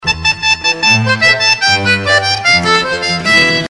intro-cueca-slow.mp3